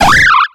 Cri de Sapereau dans Pokémon X et Y.